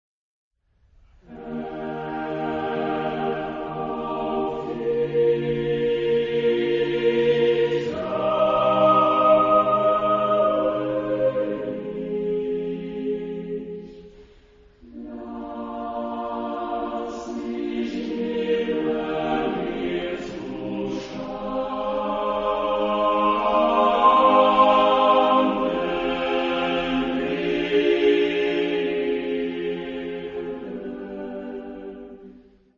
Genre-Stil-Form: Psalm ; geistlich ; Homophon ; romantisch
Charakter des Stückes: Taktwechsel ; ausdrucksvoll ; andante
Chorgattung: SSAATTBB  (8 gemischter Chor Stimmen )
Tonart(en): Es-Dur
Lokalisierung : Romantique Sacré Acappella